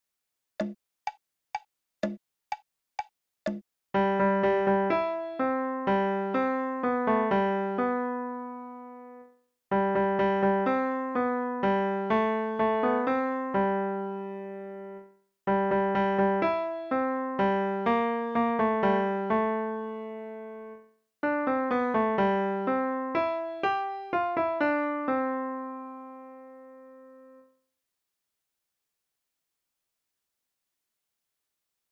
Free Piano Music!